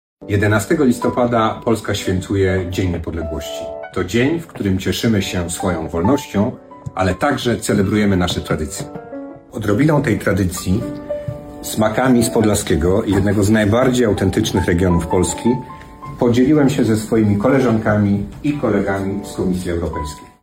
Mówił komisarz Piotr Serafin.